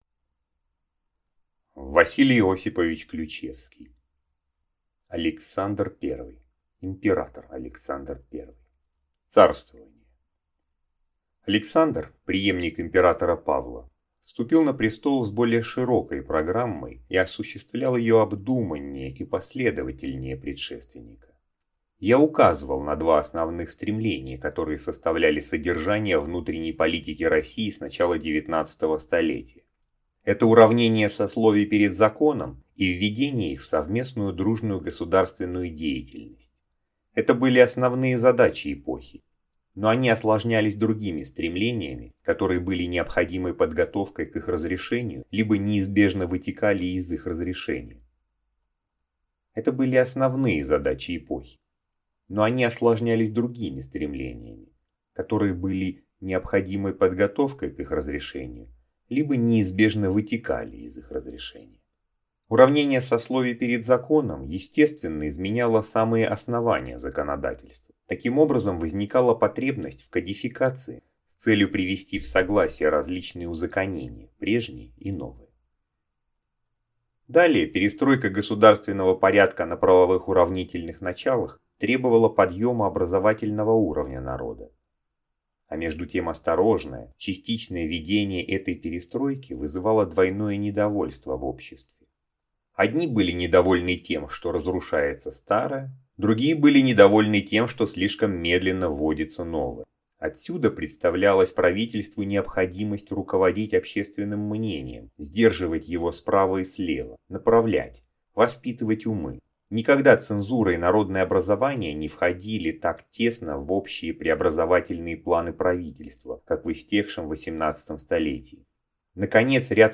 Аудиокнига Александр I | Библиотека аудиокниг